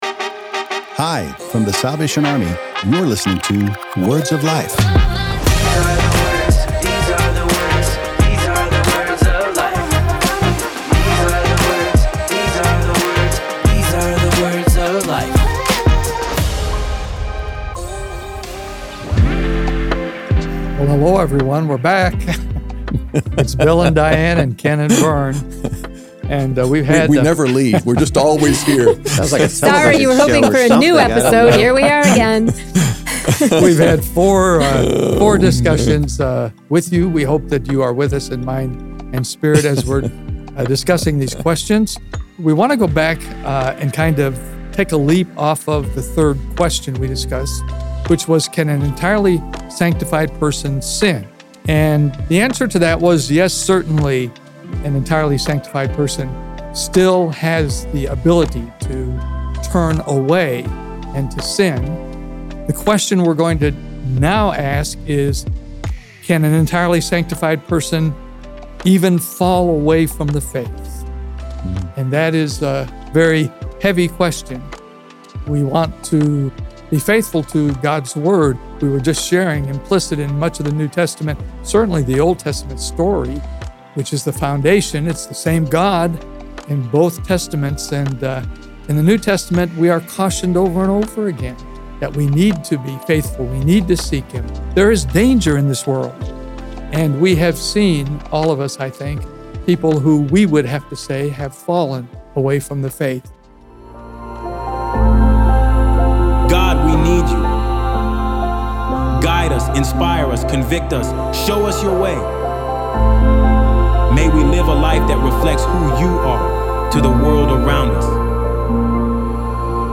As we begin to close this series the panel discusses a question that always comes up during a discussion like this.